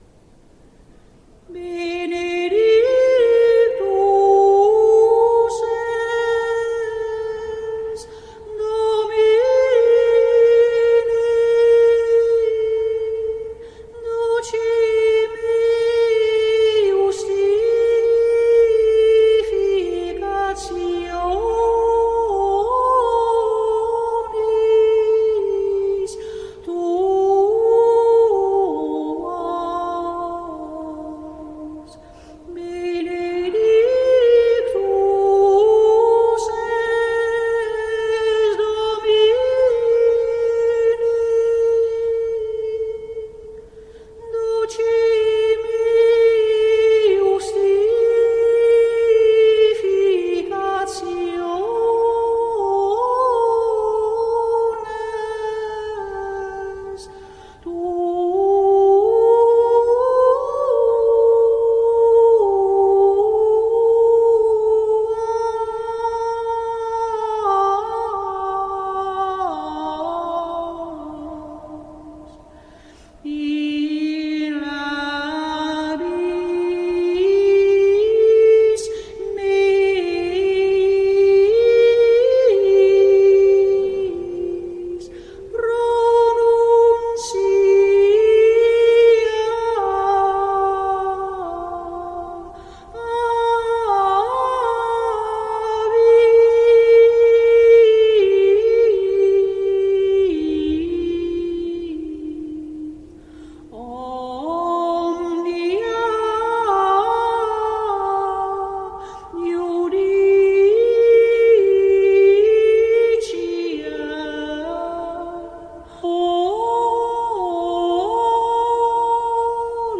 Benedictus es Domine (Ps. 118, 12.13), offertorium  WMP   RealPlayer